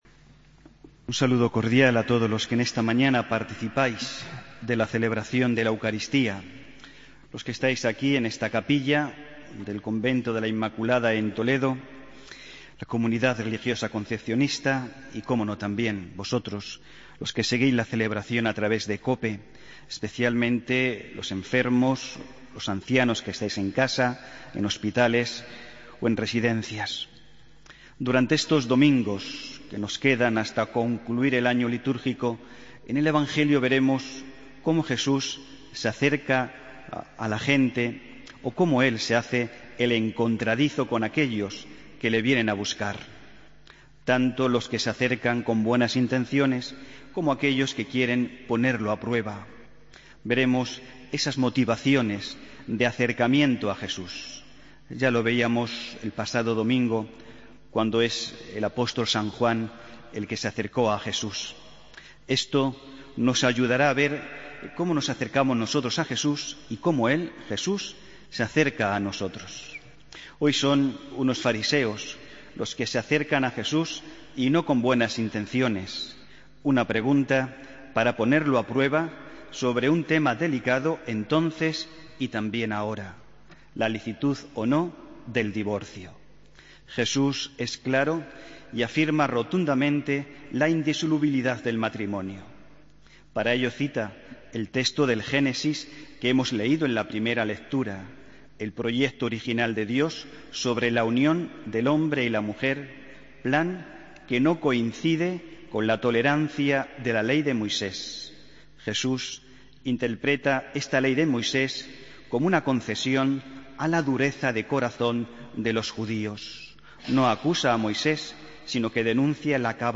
Homilía del domingo 04 de octubre de 2015